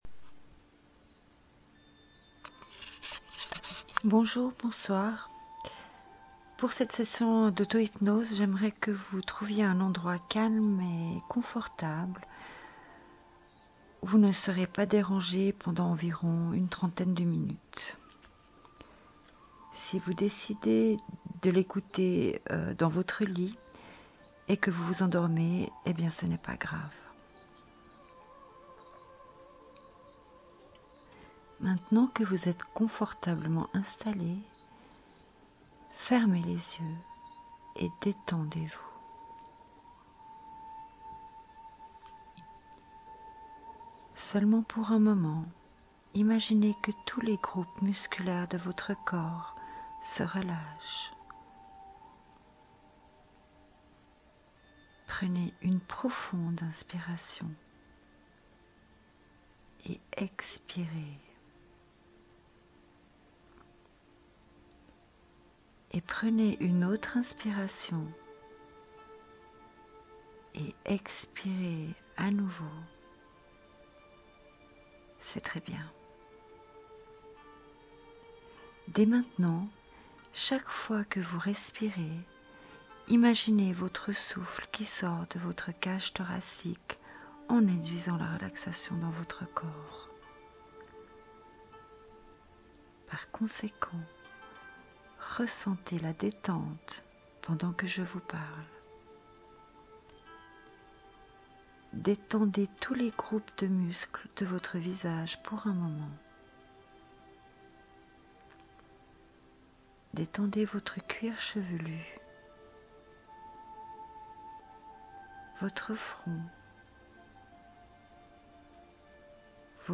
Enregistrement d’une séance d’Hypnorelaxation.
Fermez les yeux et laissez vous bercer par ma voix. Ce moment est un moment de profonde relaxation et un moment de détente que vous utiliserez lors de stress de la vie quotidienne ou avant de vous coucher pour induire l’endormissement.
Auto-Hypnose-Realaxation.mp3